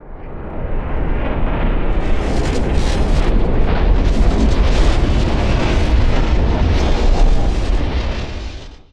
ONE_SHOT_SPACE_TEAR.wav